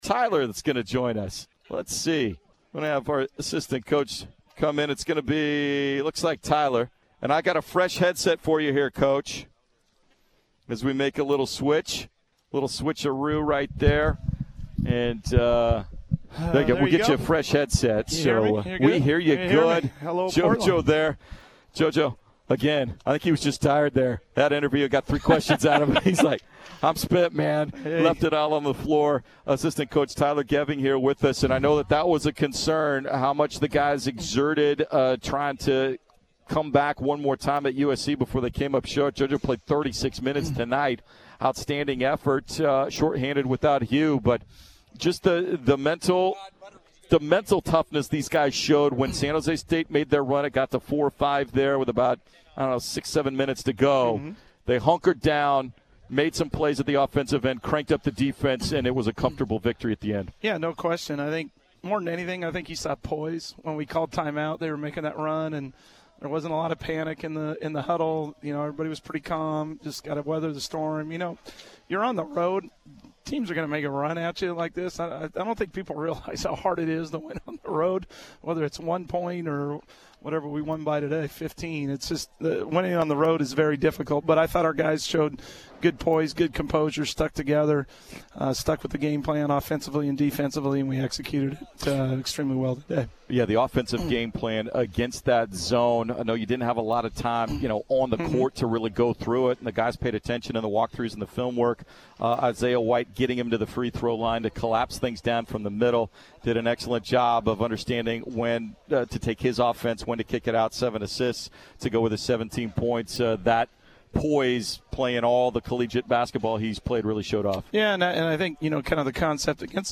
Men's Basketball Interviews
coach_postgame_interview.mp3